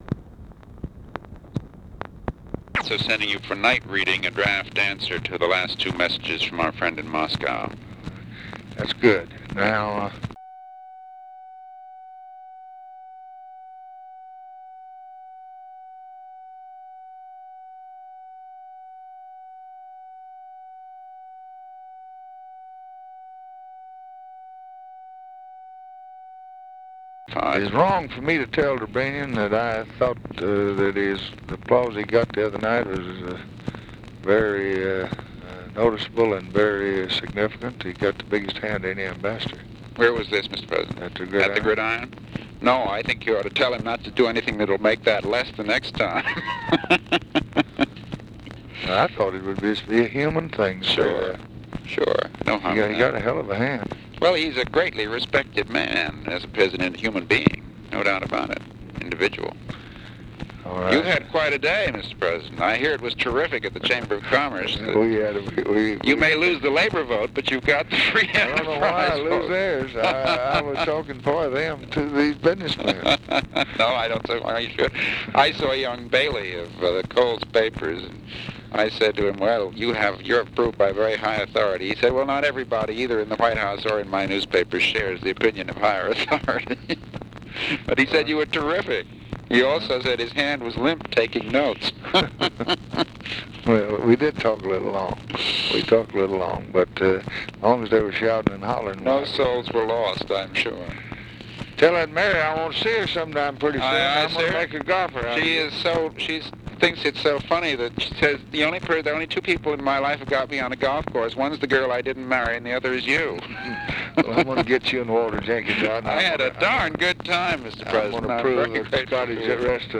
Conversation with MCGEORGE BUNDY, April 27, 1964
Secret White House Tapes